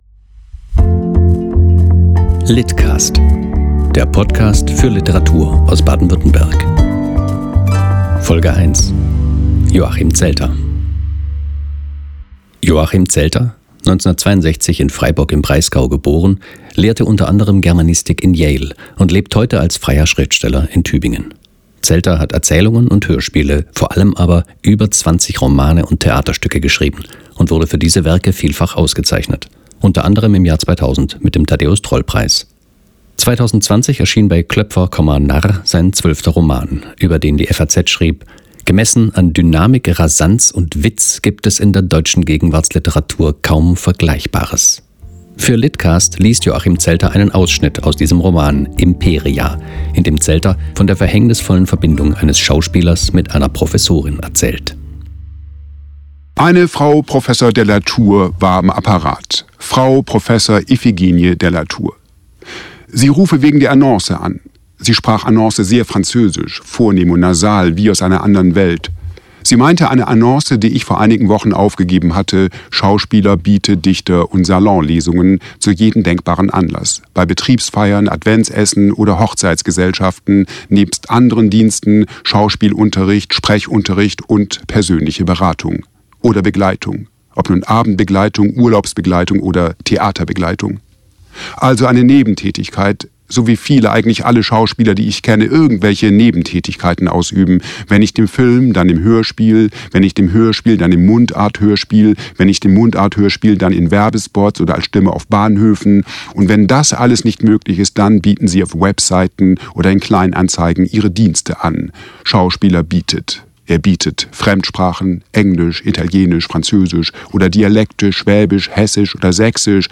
liest aus seinem Roman "Imperia"